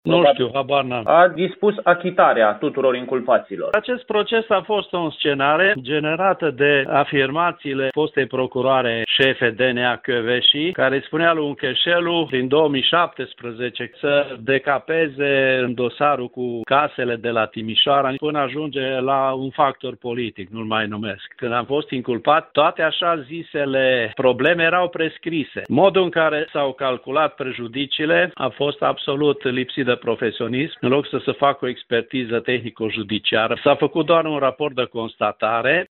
Gheorghe Ciuhandu a aflat sentința, care nu este definitivă, de la reporterul Radio Timișoara.